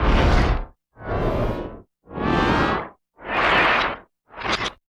99 PAN FX -L.wav